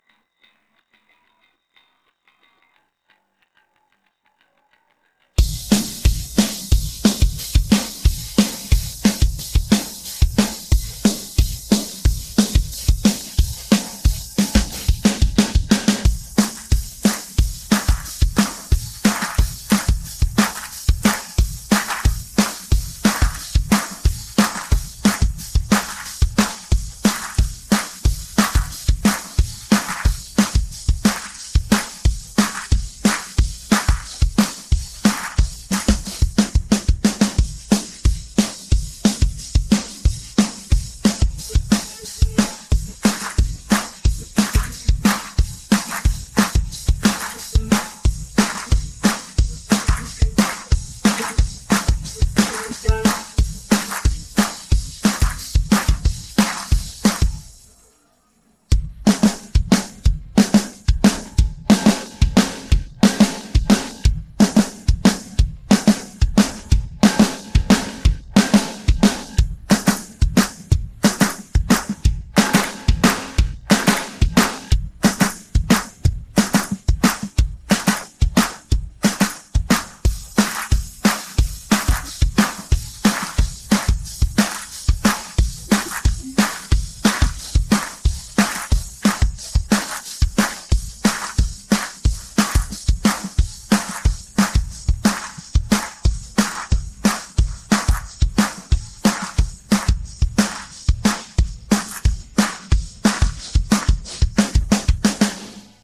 The drum separation seems much more accurate. Note that the drums don’t enter the mix for six seconds. You’ll also hear bits of other tracks around the 40-second mark.
Drums